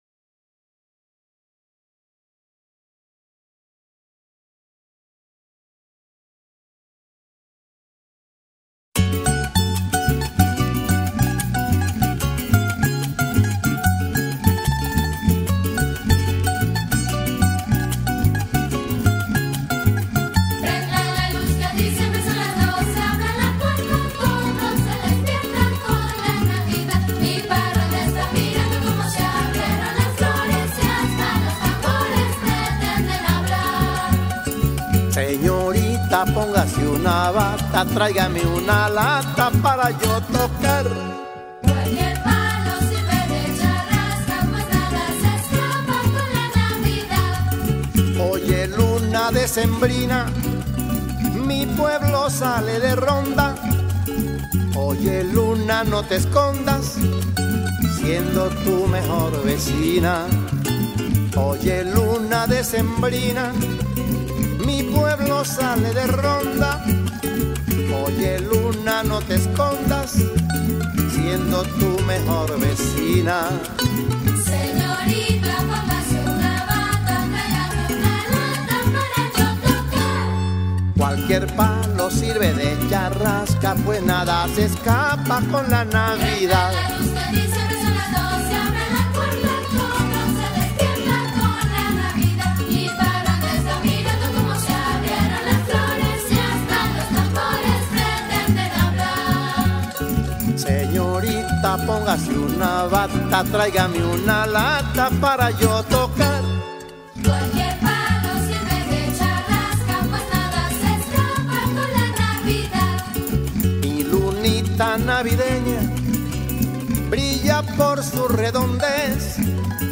parranda